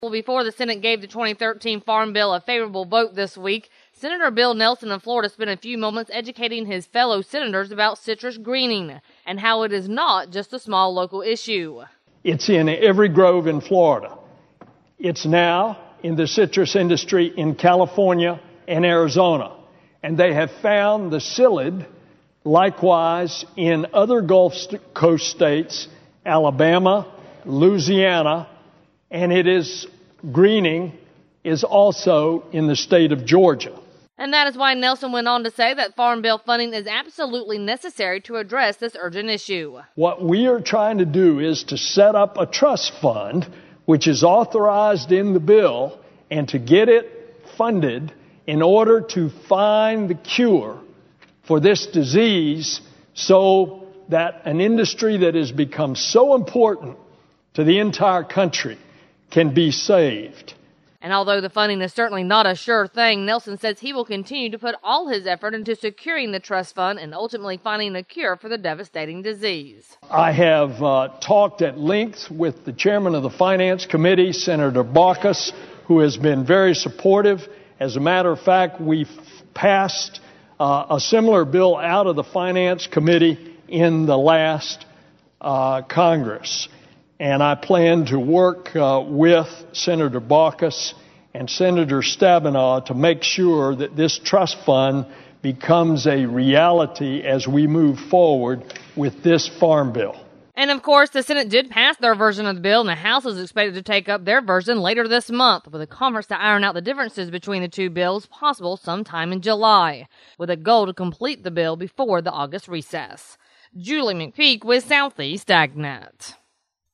During Monday’s Farm Bill debate, Senator Bill Nelson of Florida explained to his colleagues how citrus greening is just not a small local issue.